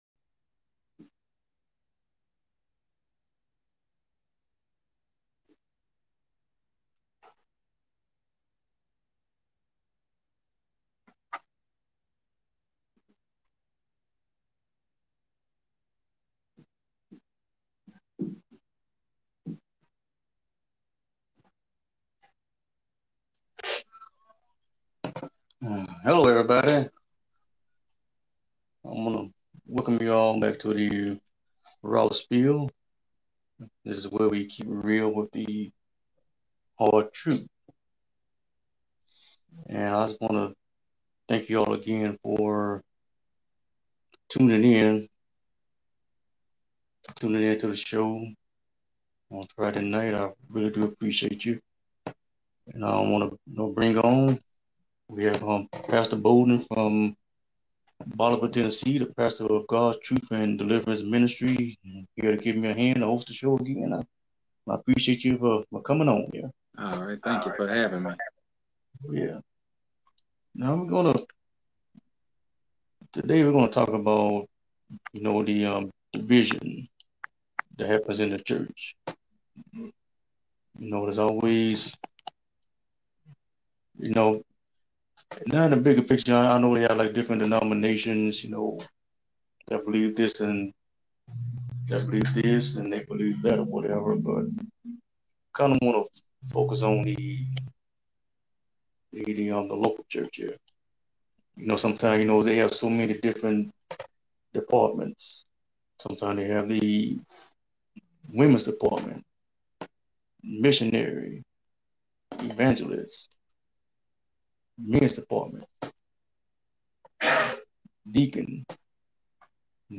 Talk Show Episode, Audio Podcast, The Raw Spill and Why Is There Church Division on , show guests , about Why Is There Church Division, categorized as Health & Lifestyle,History,Religion,Society and Culture